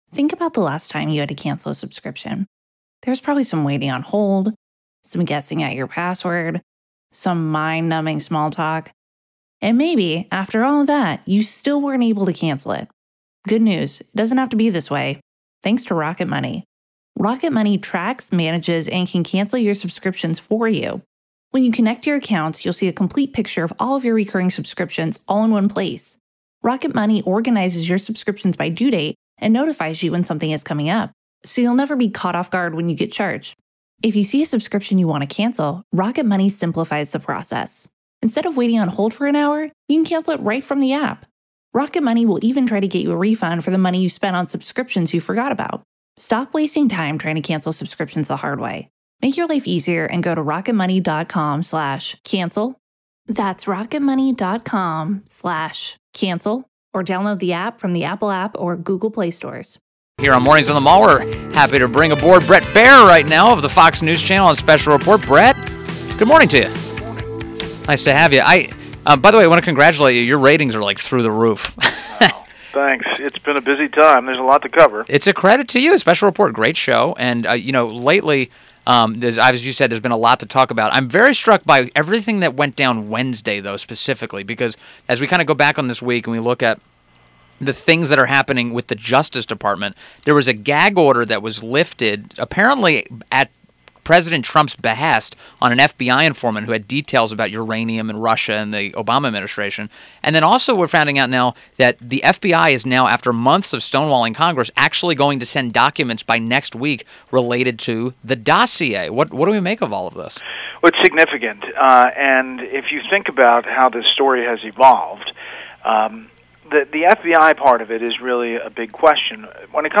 INTERVIEW - BRET BAIER - host of Special Report with Bret Baier on the Fox News Channel